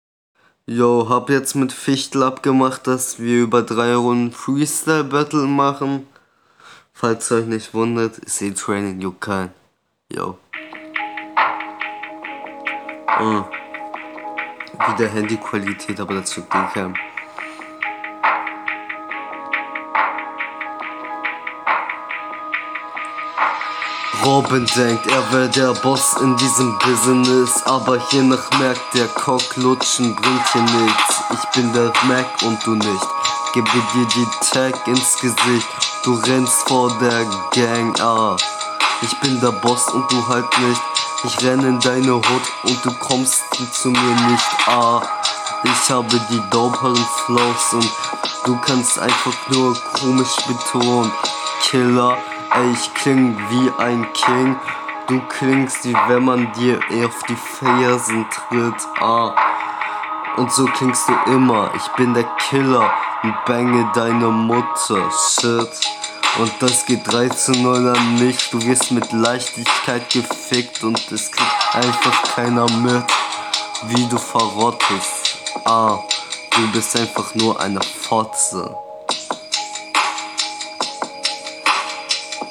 Ist halt freestyle.
Flow: Der Flow ist einfach, aber grundsätzlich vorhanden.